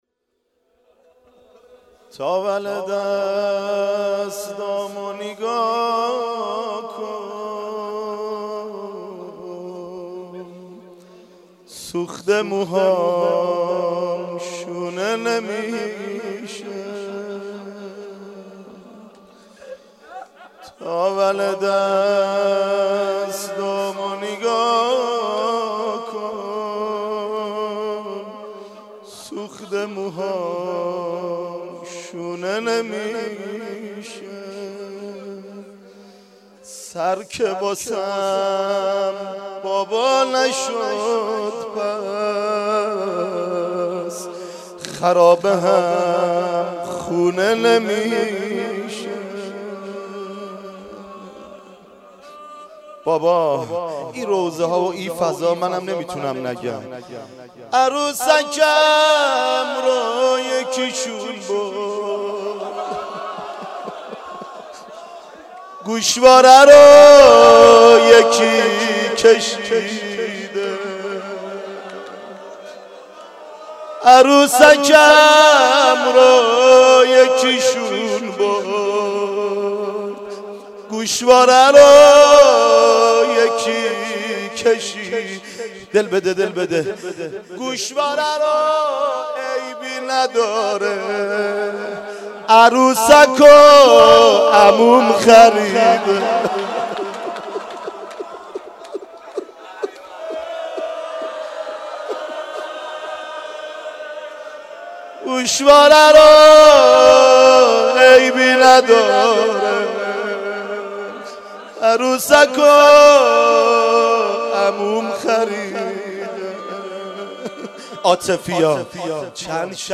مناسبت : شب سیزدهم رمضان
قالب : روضه